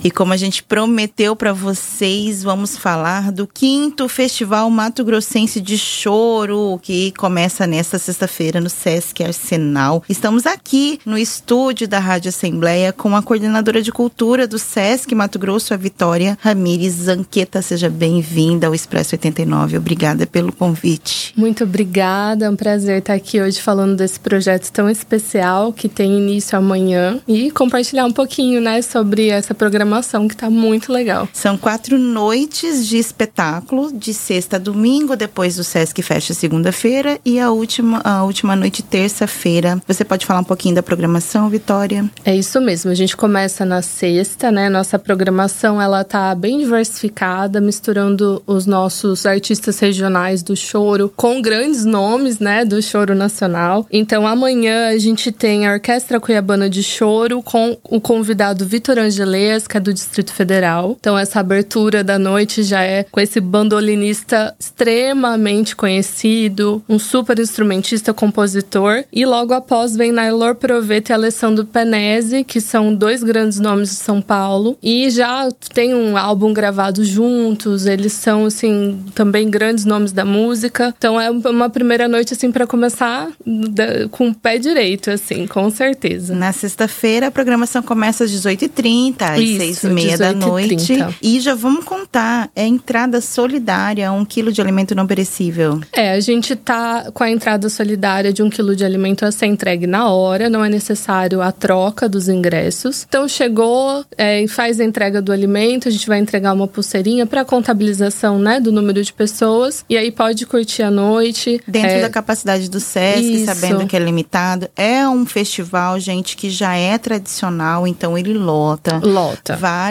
Expresso 89 - Entrevista